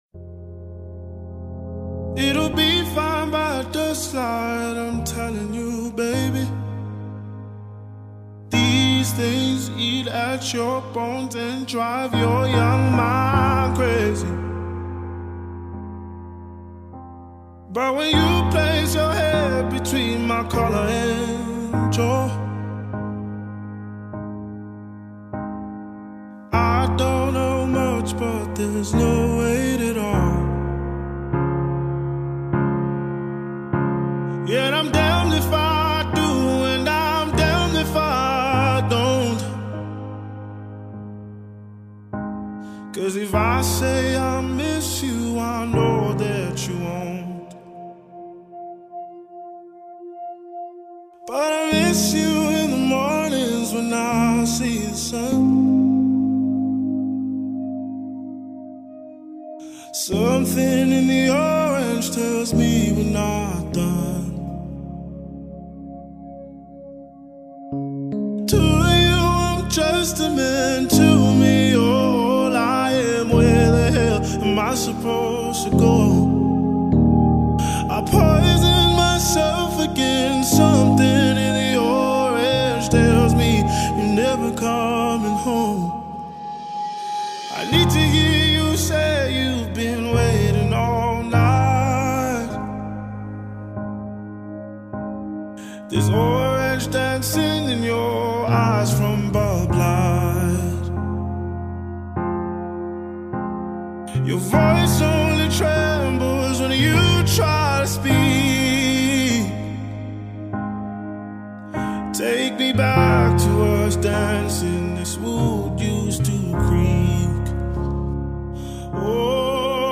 soulful voice
Through his emotive vocals and heartfelt interpretation